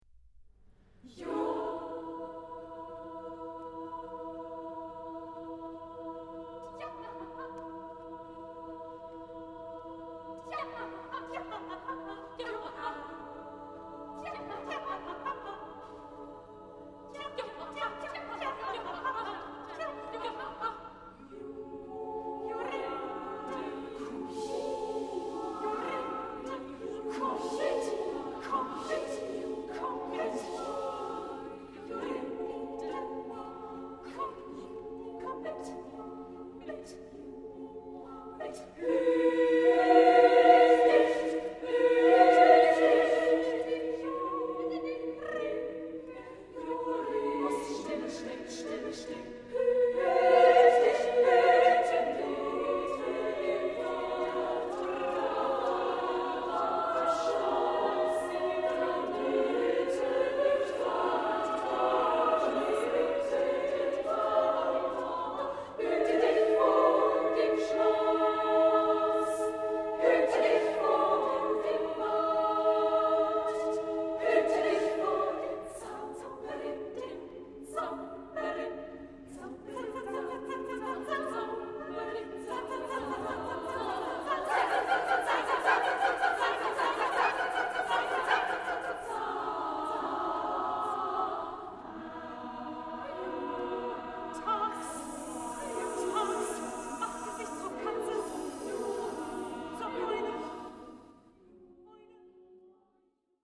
Genre-Style-Forme : contemporain ; Conte musical
Caractère de la pièce : mystérieux
Type de choeur : SSAA (div)  (12 voix égales de femmes )